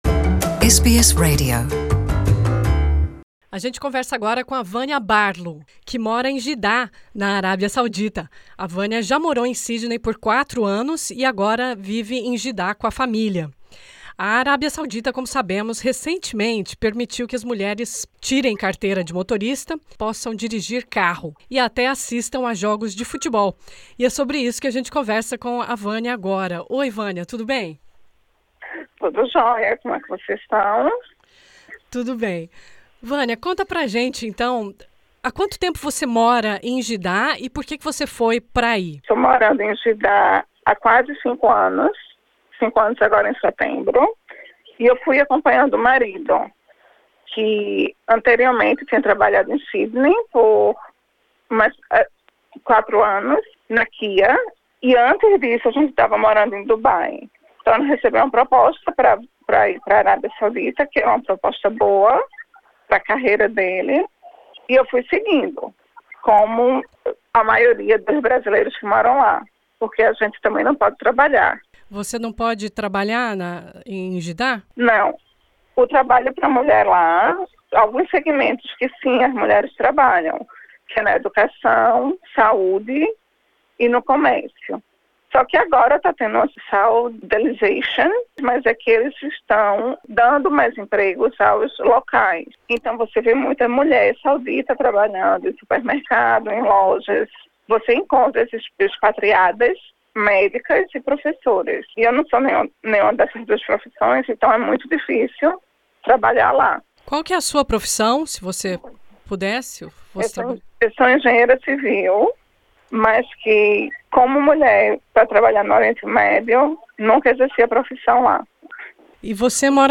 Nessa entrevista